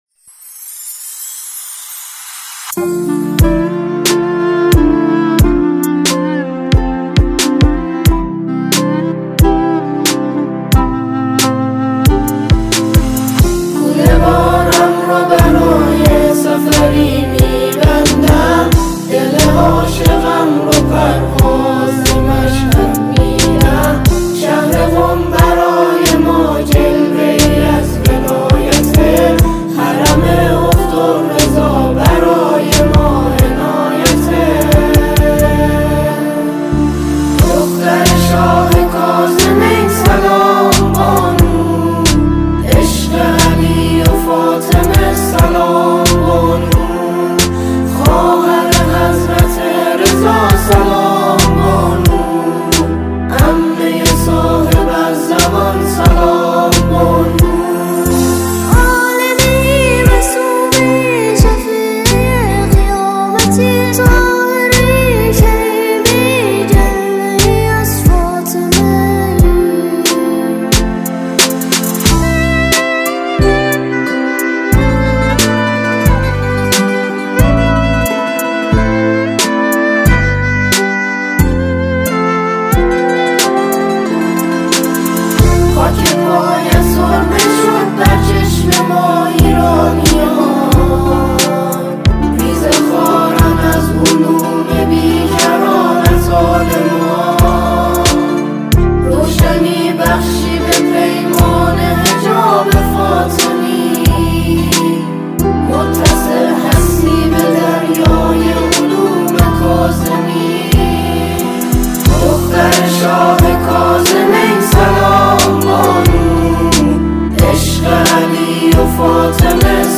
همخوانان